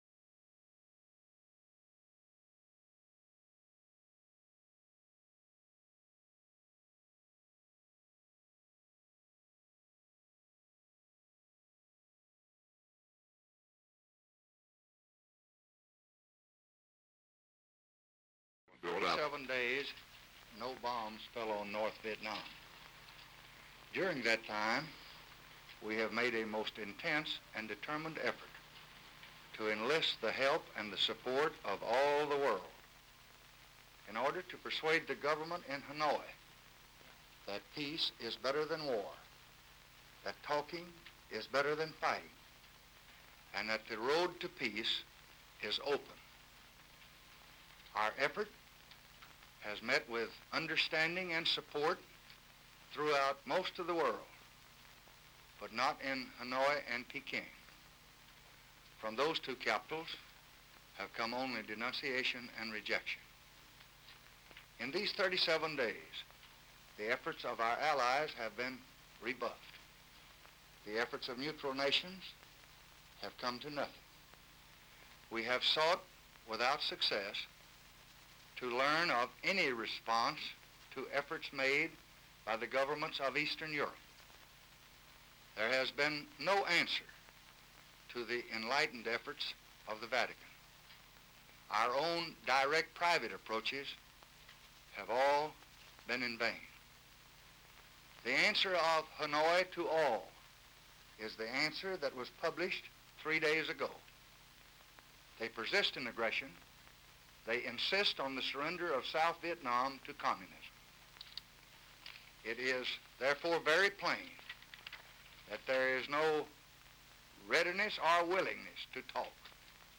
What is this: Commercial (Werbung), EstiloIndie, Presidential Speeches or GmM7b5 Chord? Presidential Speeches